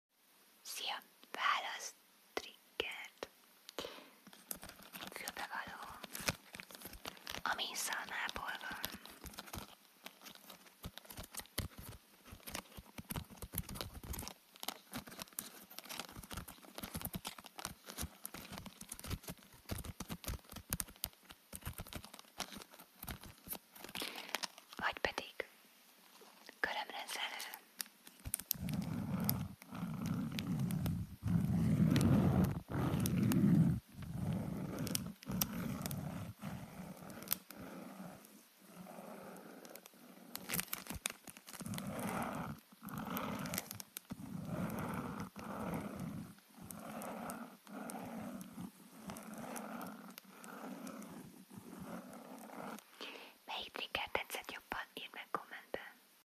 ASMR